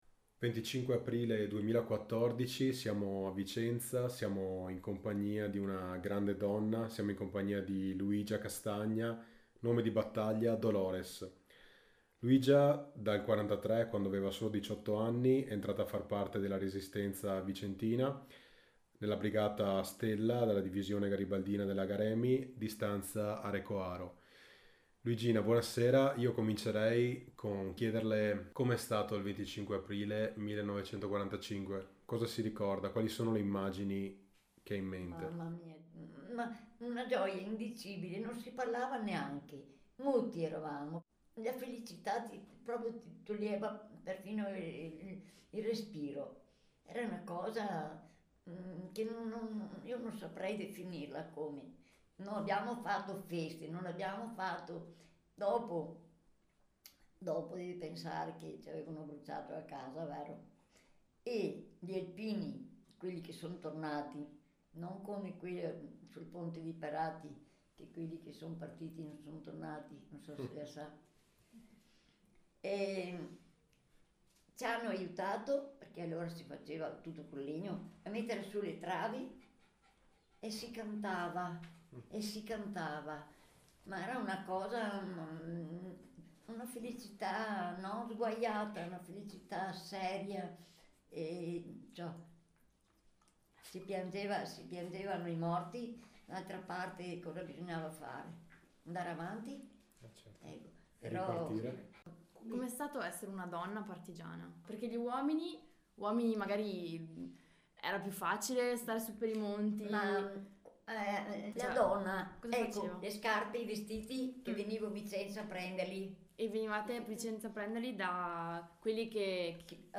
INTERVISTA BUCOLICA